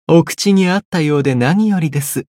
觉醒语音 能合您的口味真是太好了 お口に合ったようで何よりです 媒体文件:missionchara_voice_601.mp3